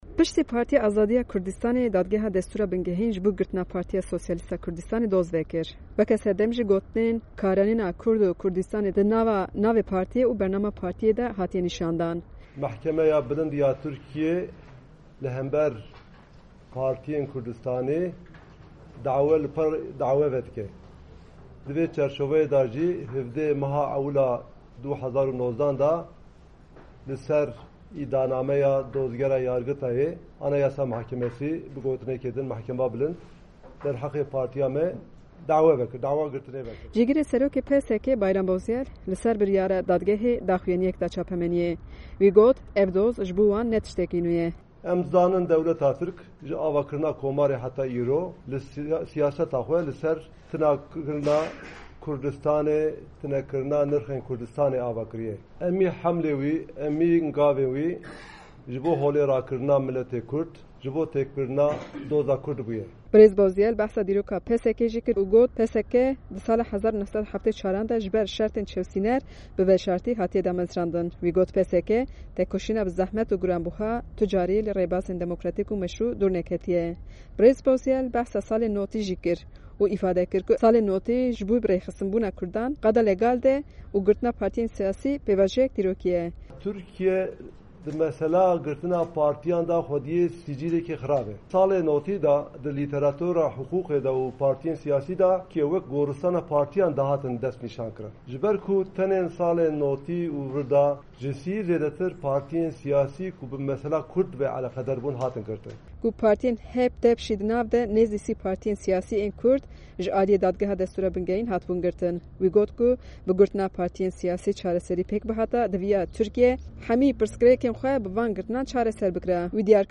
Amed